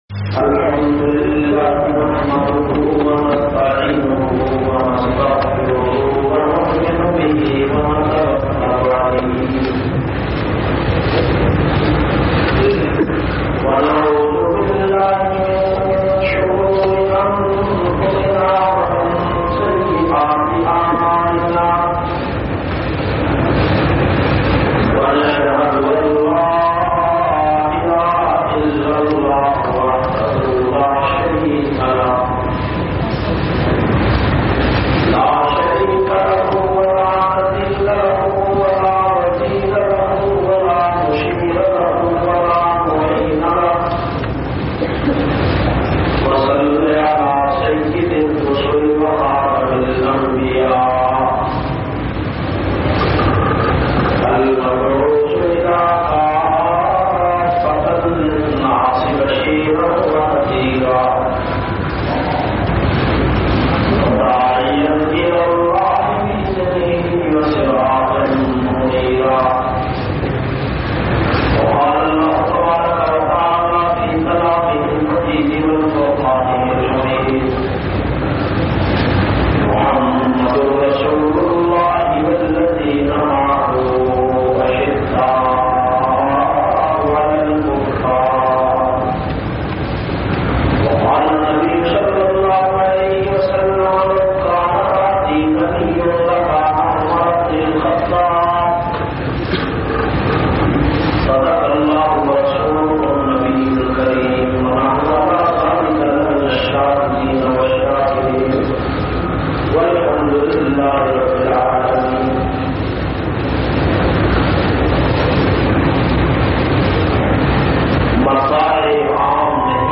520- Shahadat e Damad e Ali Jumma khutba Jamia Masjid Muhammadia Samandri Faisalabad.mp3